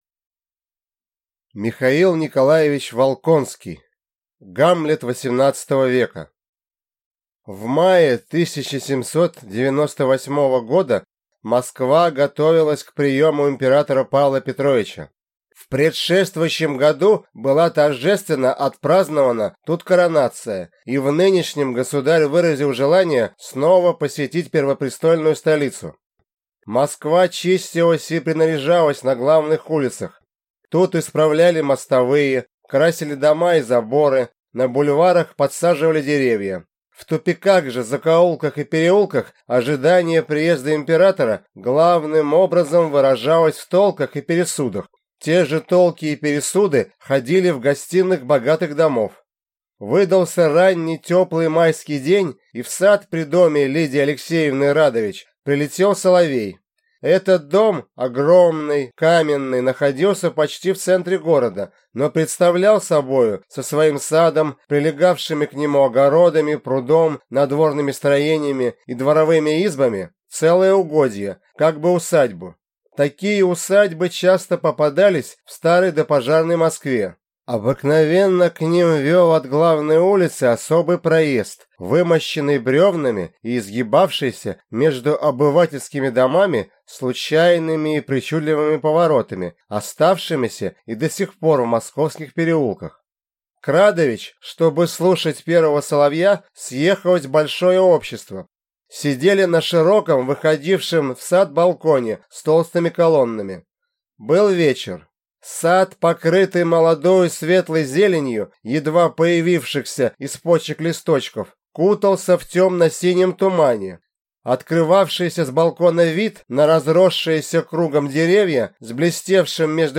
Аудиокнига Гамлет XVIII века | Библиотека аудиокниг